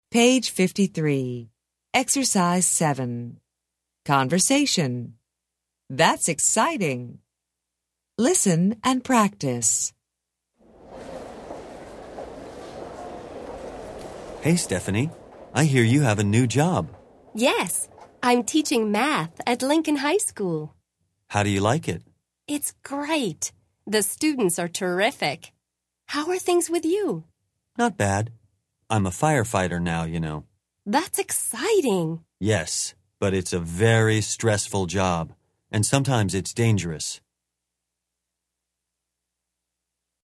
Interchange Third Edition Intro Unit 8 Ex 7 Conversation Track 23 Students Book Student Arcade Self Study Audio
interchange3-intro-unit8-ex7-conversation-track23-students-book-student-arcade-self-study-audio.mp3